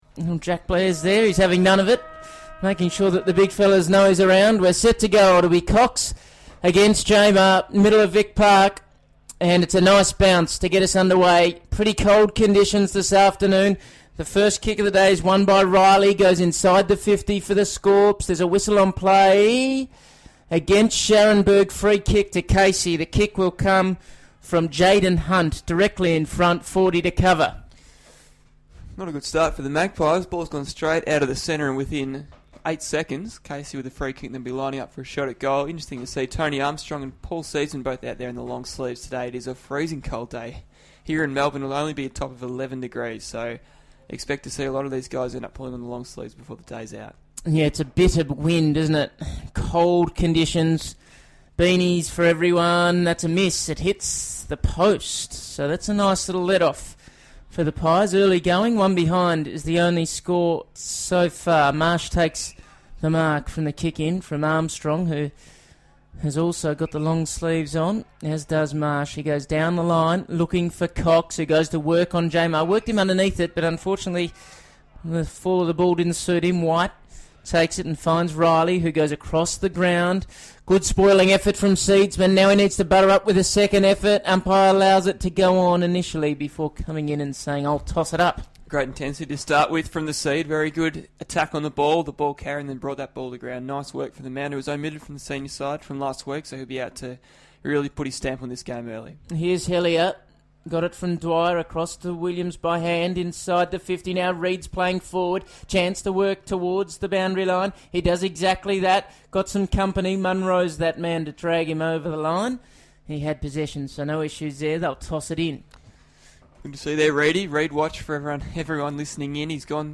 Listen to Collingwood Radio's commentary of the first quarter of Collingwood's clash with the Casey Scorpions at Victoria Park in round 15, 2015.